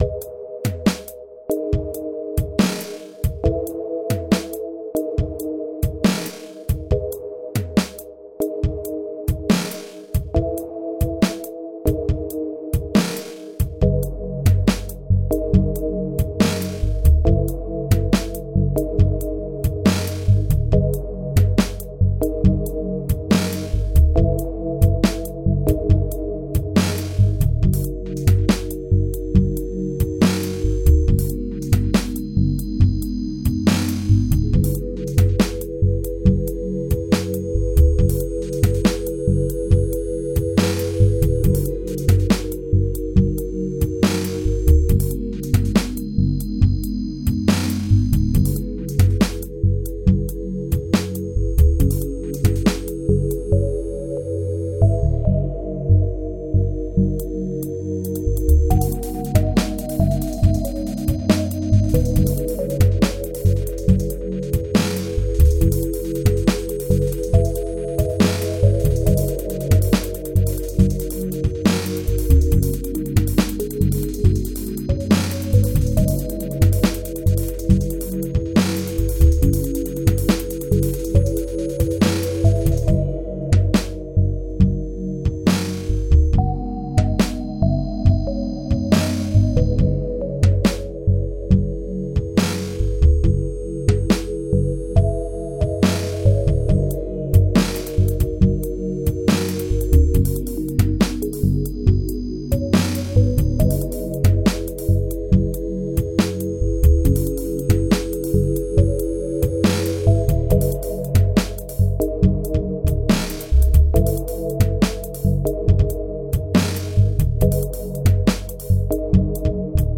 music, demo, electronica